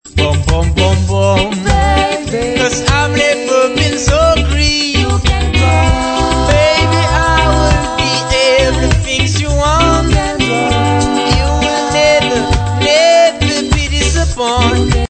rock steady